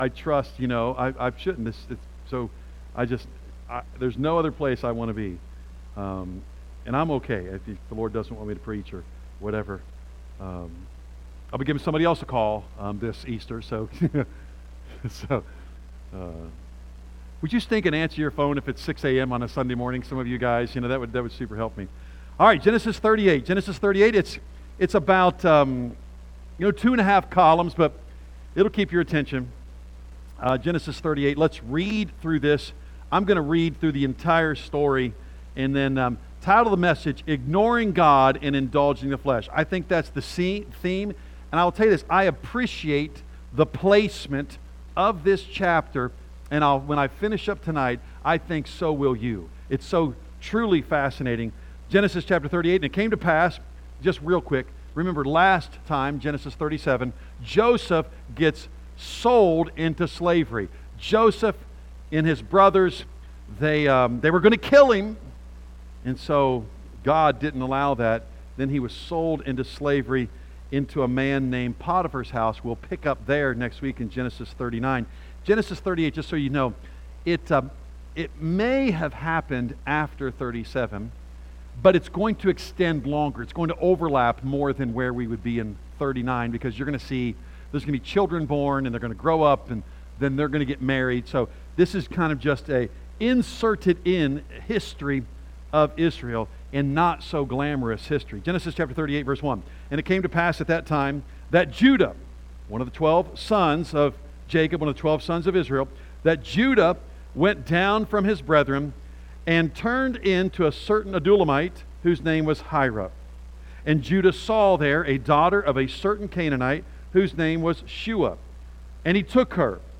A message from the series "Genesis."